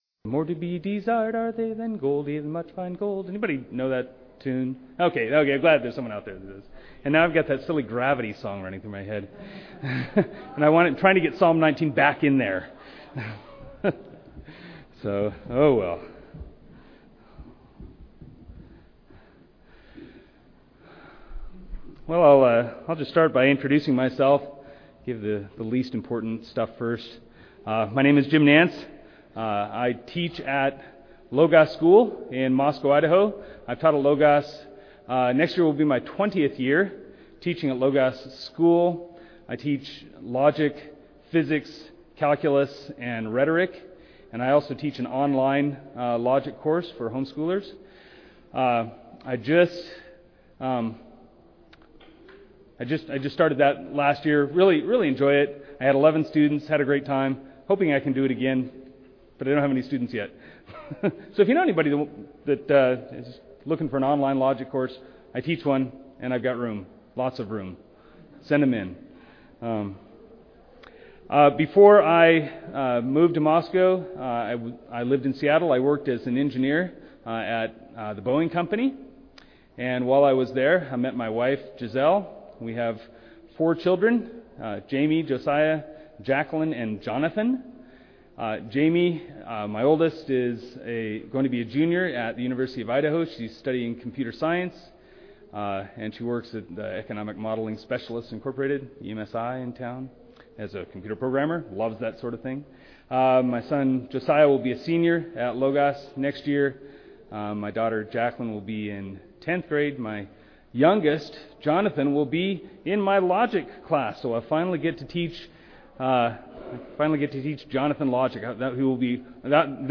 2009 Workshop Talk | 1:09:08 | 7-12, Logic